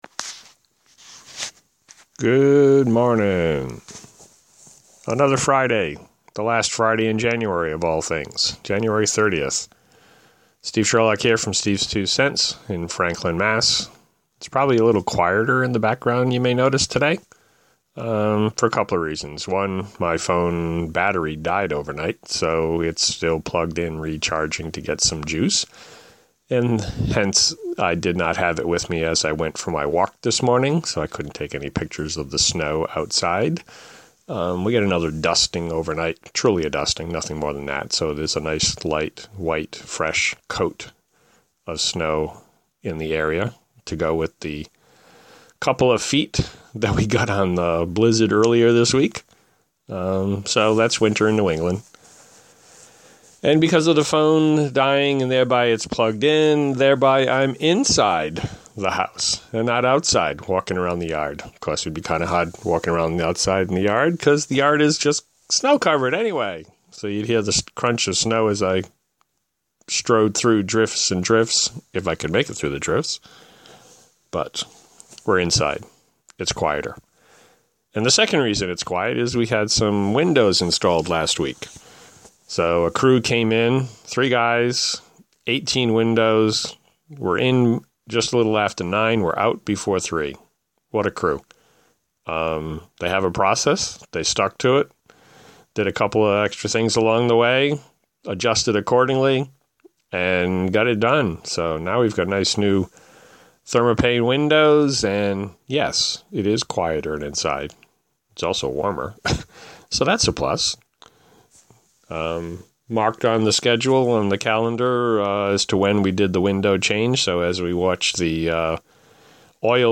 recording inside today for a couple of reasons, hence it is quieter than normal